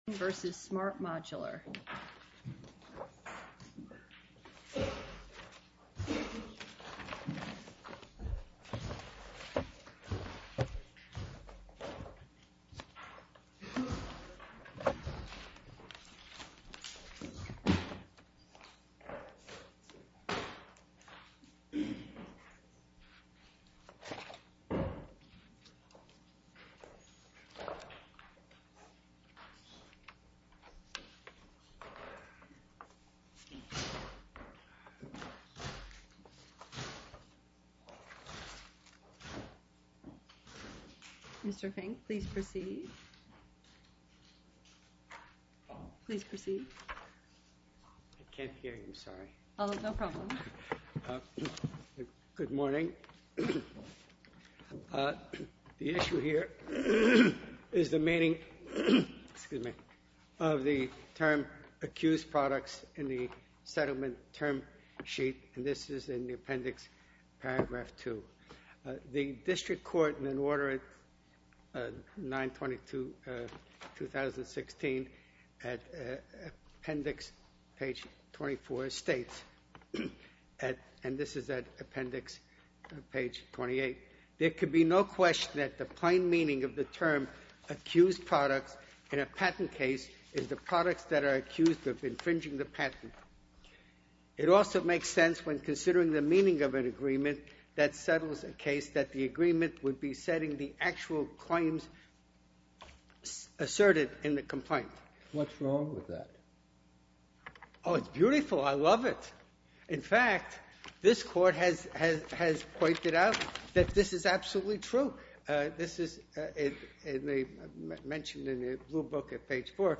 Oral argument audio posted: